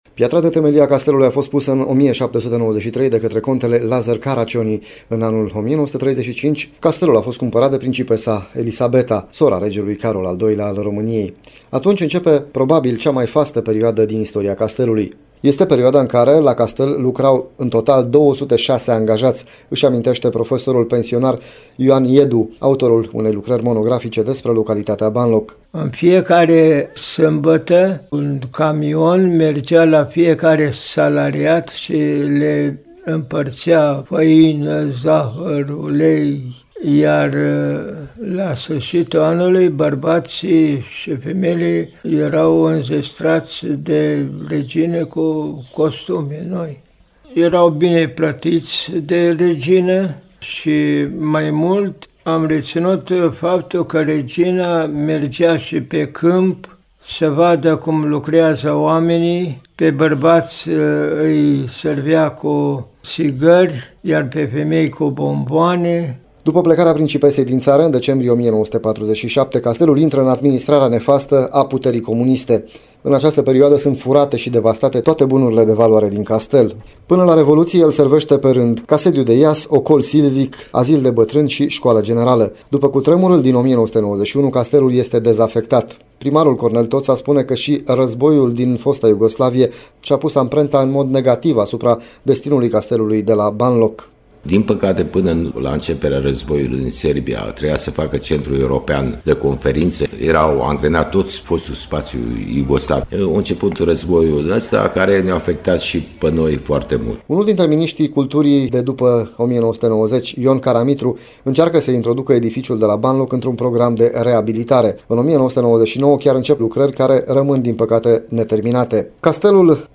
Primarul Cornel Toţa spune că şi războiul din fosta Iugoslavie şi-a pus amprenta în mod negativ asupra castelului.